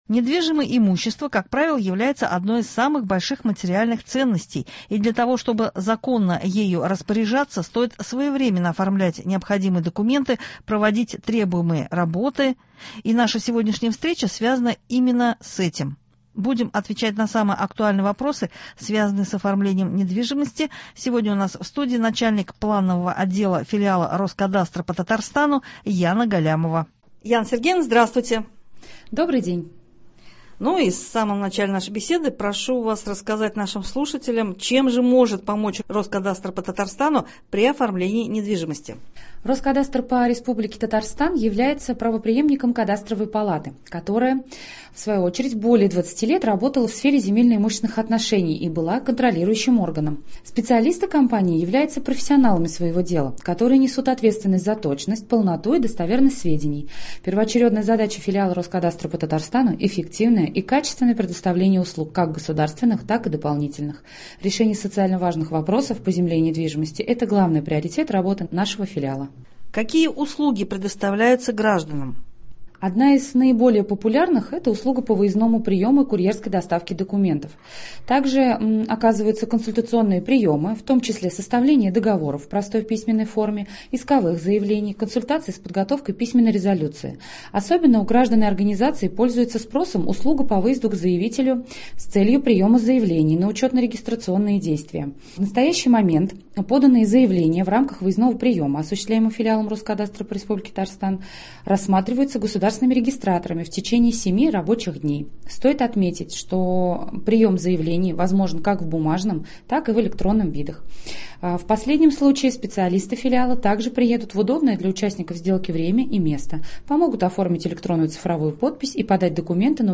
Сегодня у нас в студии